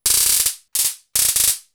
• taser electricity crackles.wav
Recorded my stun gun in a small apartment studio, crackling few times. Recorded with a Tascam DR-40
taser_electricity_crackles_cM8.wav